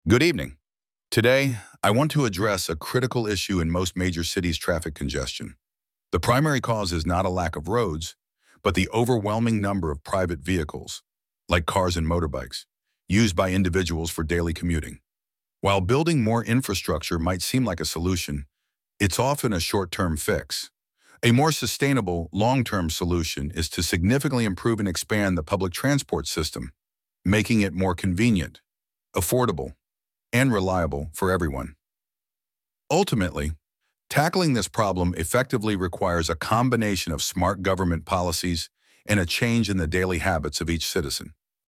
Part 1: You will hear a transport expert talking about traffic problems in big cities.